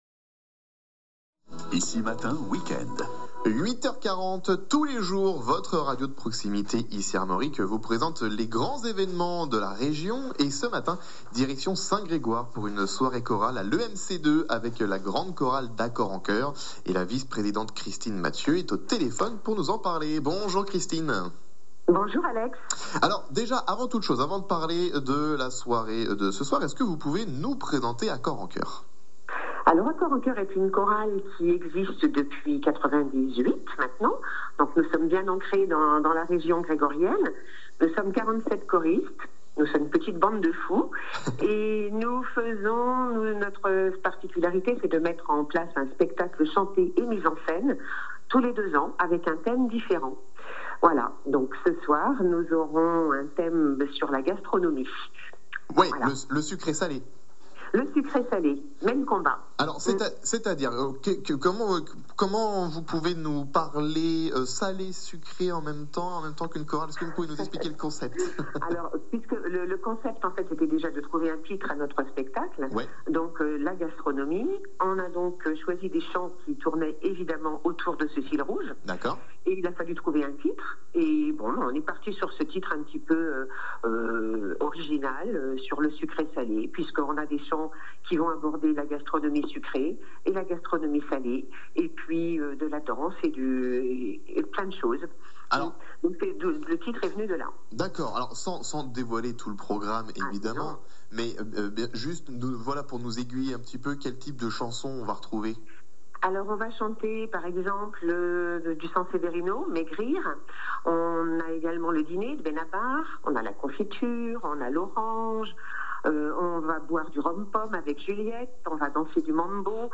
Ensemble vocal Accord en Choeur Saint-Grégoire près de Rennes, chanson française, spectacle chanté et mis en scène